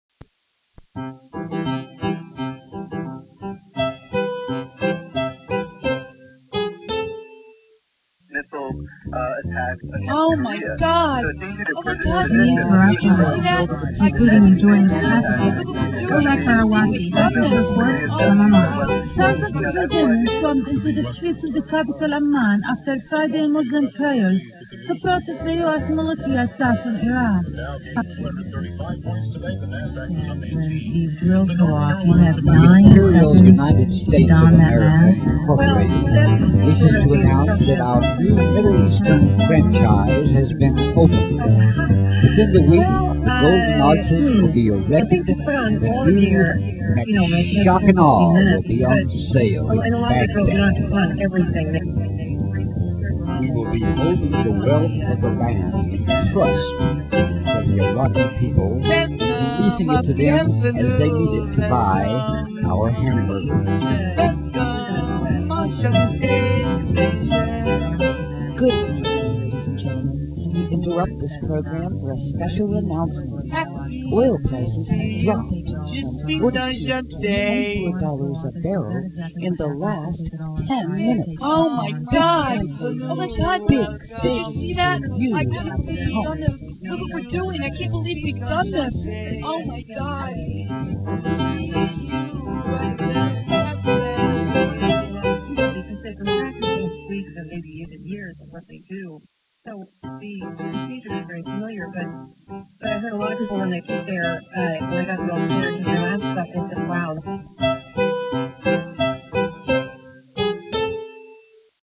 The television is showing Shock and Awe.
Someone has their cell phone recorder on.
I lied about the cell phone recording, we did that montage after we got home.